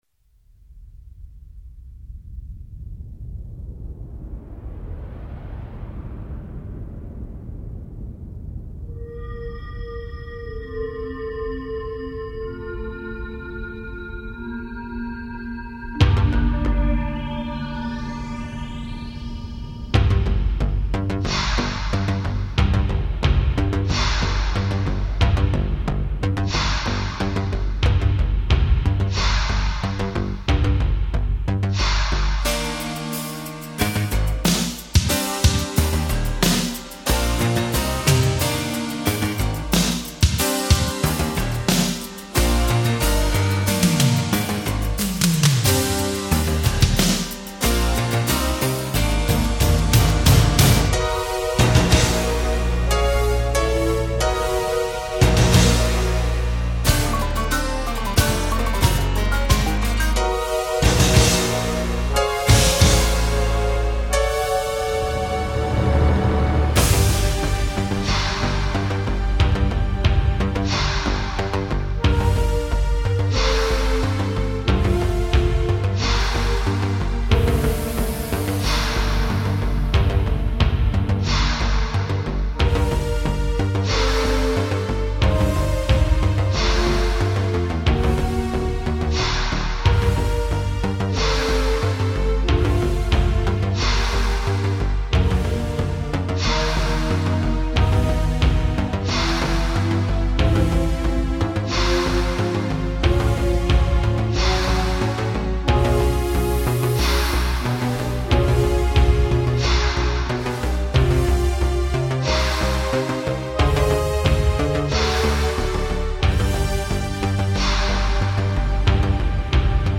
Esta canción es instrumental, y no tiene letra.